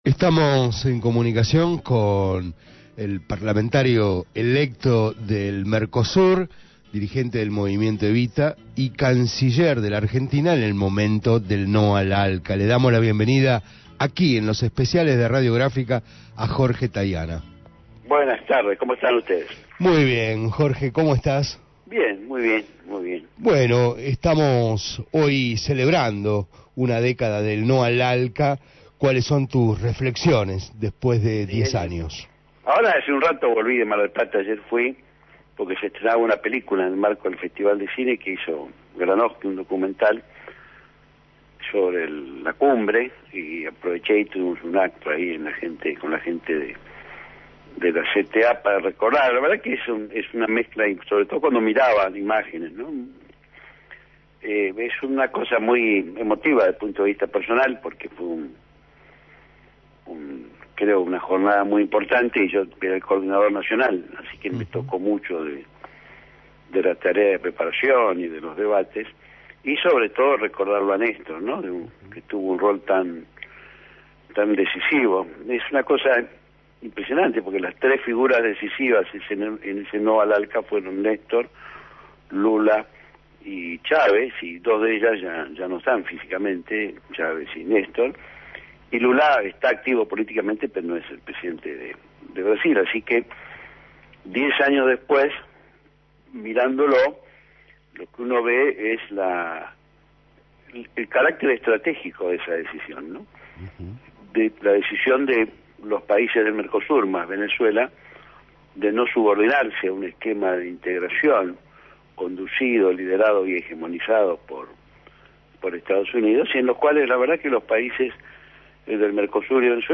Jorge Taiana, diputado electo del Parlasur por el Frente Para la Victoria y ex Canciller, conversó con el equipo peridodístico de Especiales Radio Gráfica sobre el legado del NO al ALCA diez años después de la cumbre de Mar del Plata.